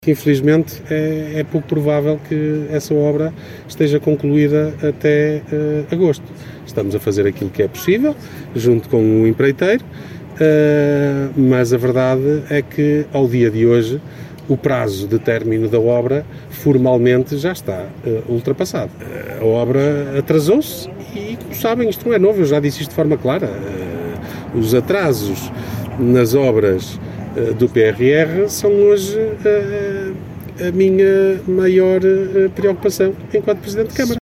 Declarações de Ricardo Araújo, presidente da Câmara Municipal de Guimarães.